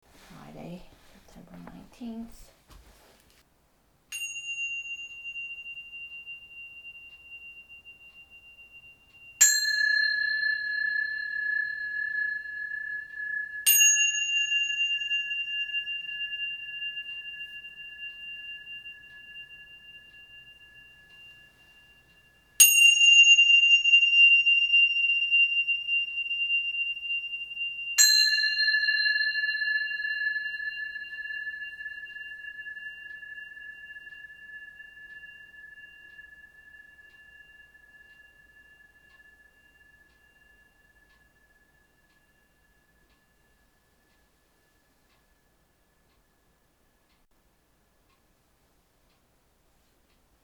It is drizzling out here this evening. But it's not a dreary drizzle.
everything shimmers (audio). Just a little bit.